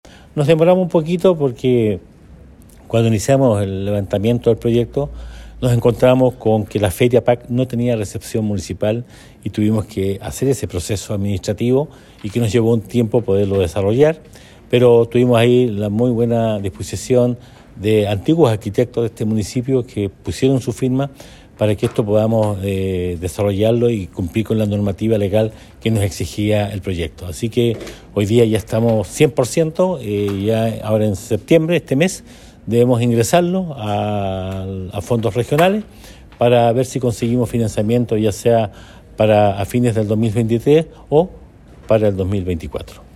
El alcalde Carrillo, señaló que el proceso tardó un poco más de lo esperado ya que la Feria Pedro Aguirre Cerda no tenía recepción municipal, por lo que una vez que se terminó ese proceso, se puede ingresar el proyecto a los fondos regionales.